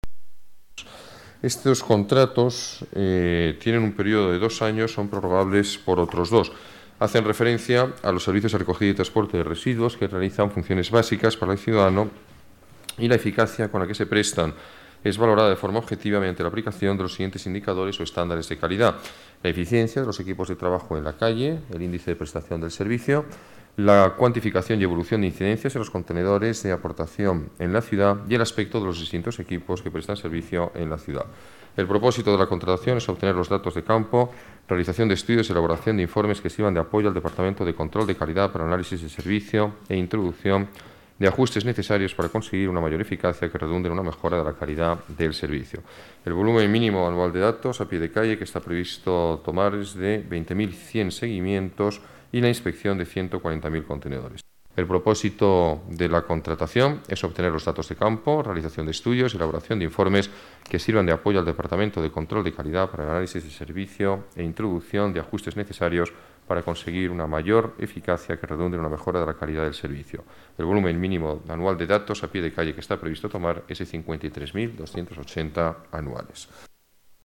Nueva ventana:Declaraciones alcalde, Alberto Ruiz-Gallardón: más eficacia en la limpieza de residuos